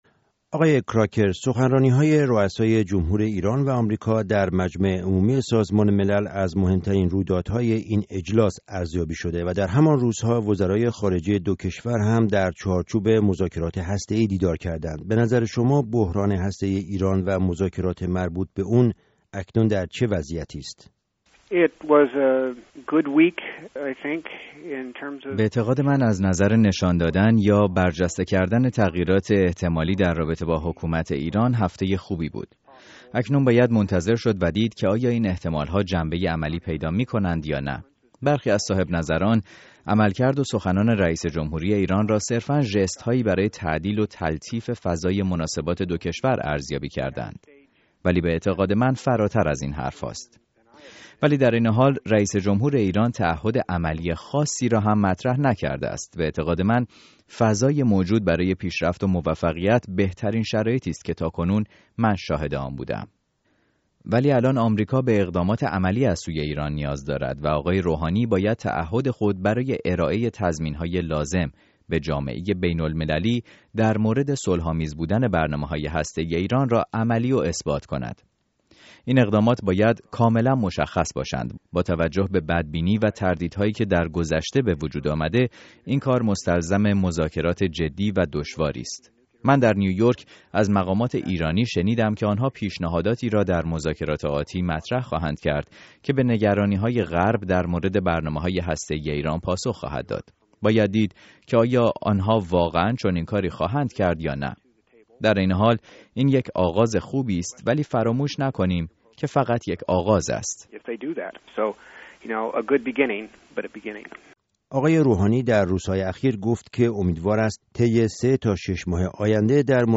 گفتگوی رادیو اروپای آزاد/ رادیو آزادی با رایان کراکر